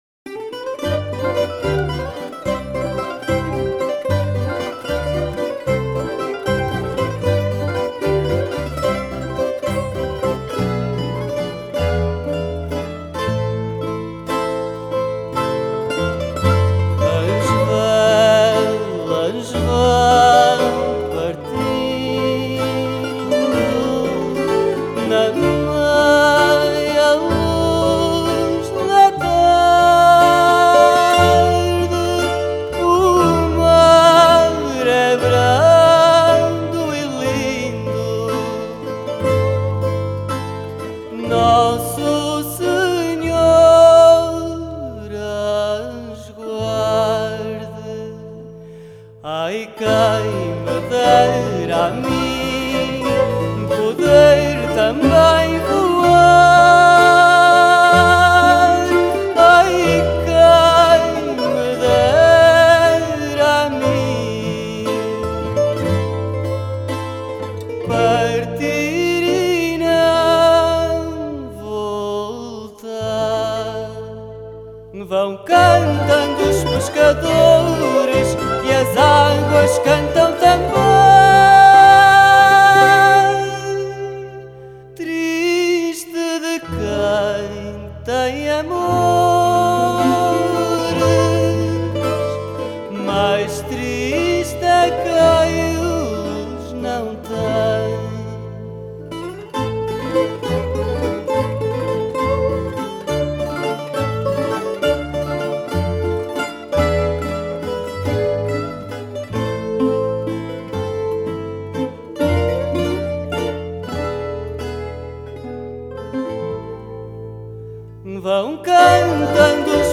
Genre: Folk, World, Fado